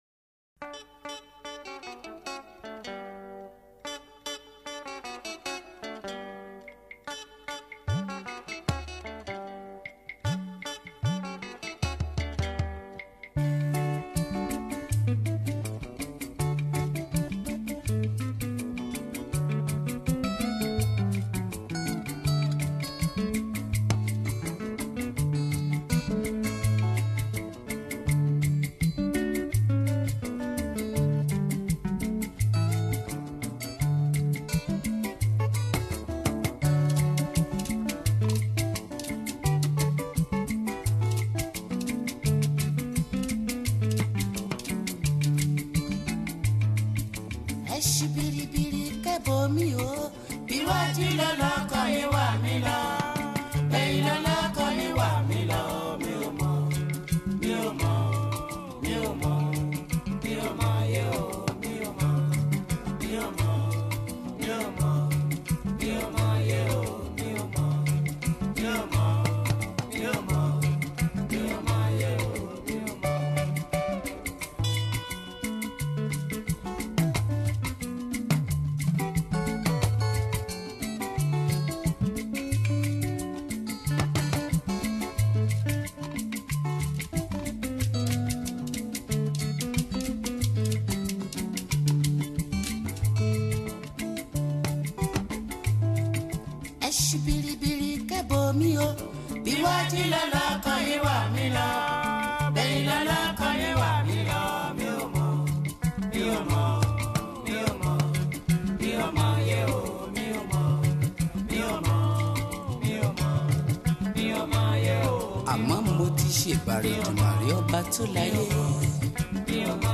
Juju, Yoruba Highlife